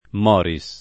vai all'elenco alfabetico delle voci ingrandisci il carattere 100% rimpicciolisci il carattere stampa invia tramite posta elettronica codividi su Facebook Morris [ingl. m 0 ri S ] pers. m. (= Maurizio) e cogn. — italianizz. come pers. m. in Morris [ m 0 rri S ] o Moris [ m 0 ri S ]